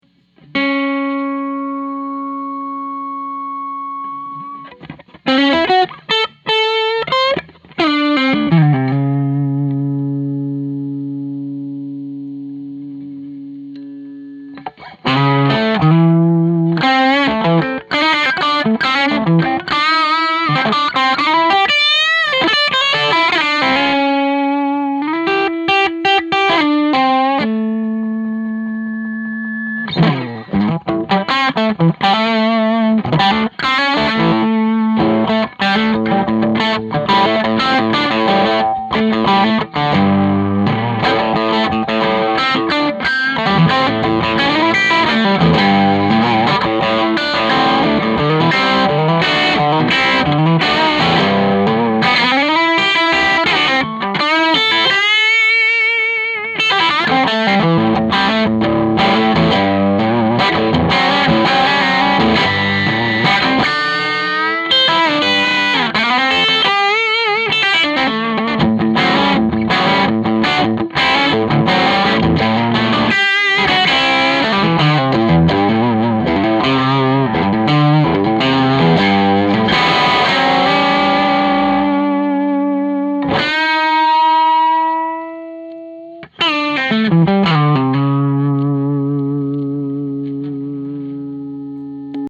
3. Gibson 335 Dirty Boogie with overtones
ODS_335_Dirty_Boogile_with_overtones.mp3